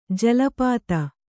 ♪ jala pāta